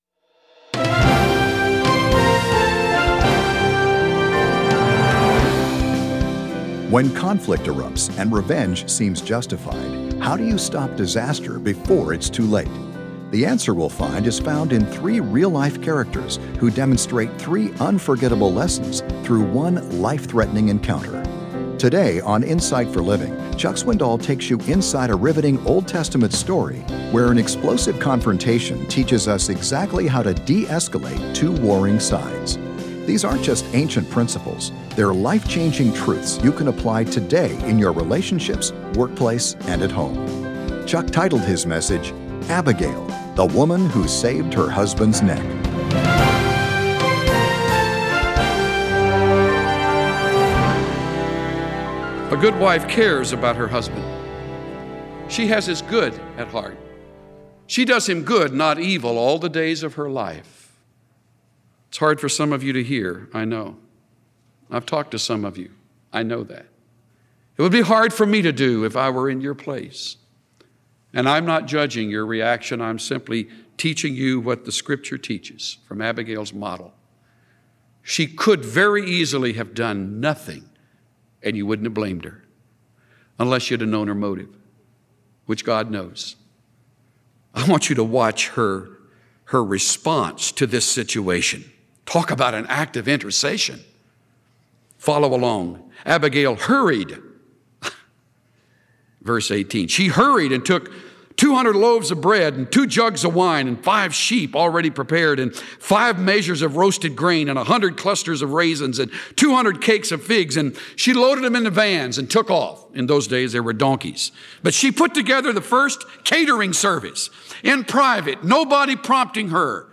Insight for Living Daily Broadcast Abigail: The Woman Who Saved Her Husband’s Neck, Part 2 Play episode February 10 Bookmarks Episode Description 1 Samuel 25 A godly wife, a foolish husband, and a rash soldier overlap in a fascinating story found in 1 Samuel 25. Follow along as Pastor Chuck Swindoll explores the story of Abigail and Nabal as they meet the future king, David.